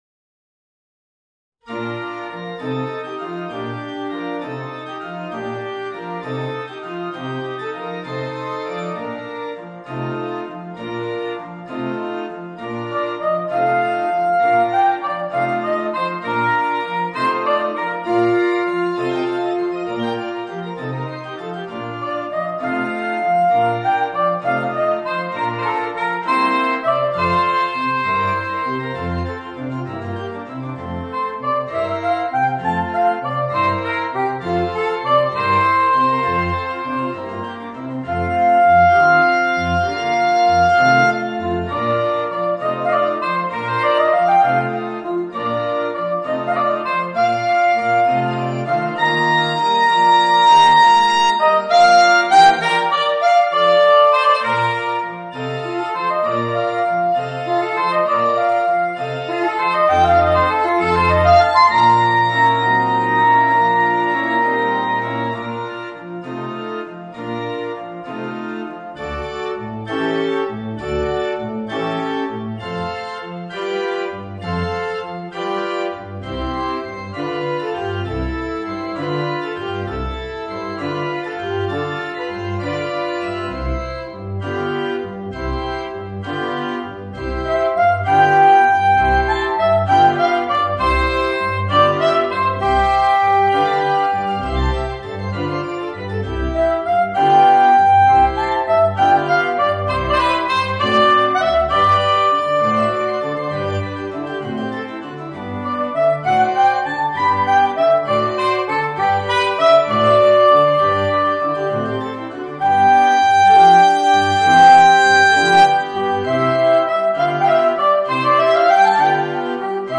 Voicing: Soprano Saxophone and Organ